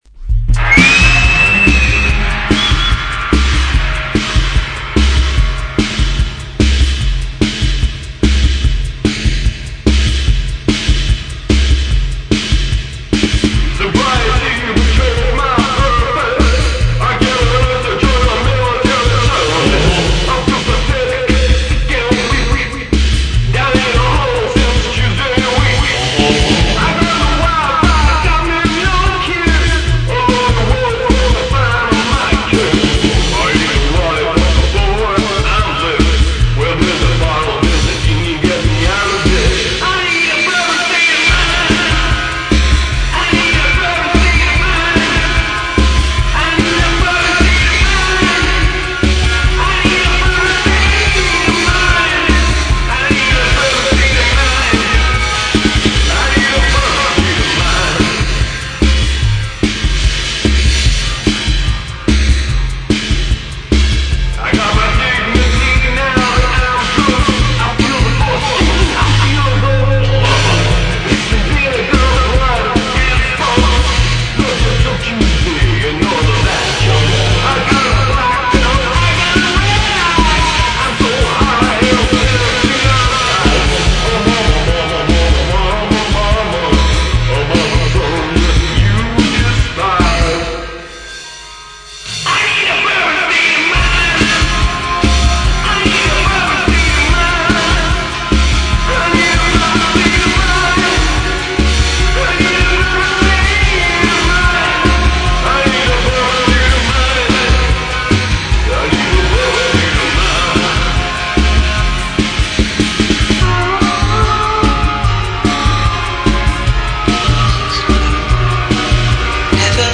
I got deep into Country music in mid 2006.